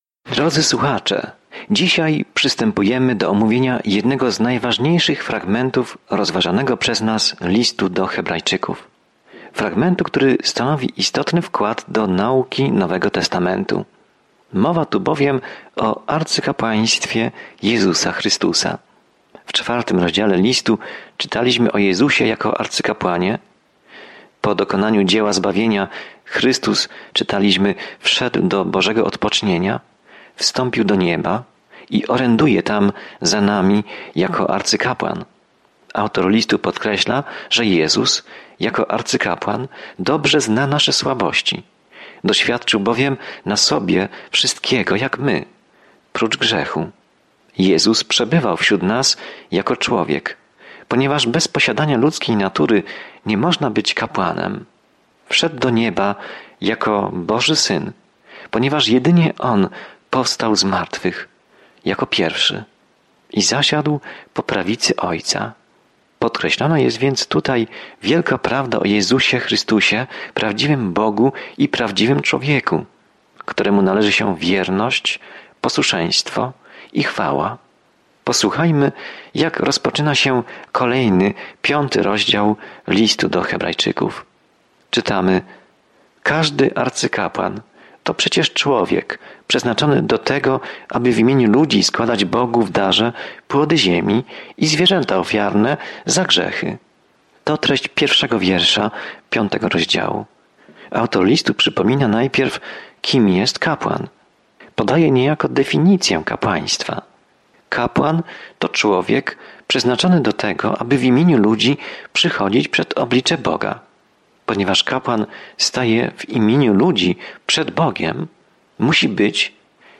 Codziennie podróżuj po Liście do Hebrajczyków, słuchając studium audio i czytając wybrane wersety słowa Bożego.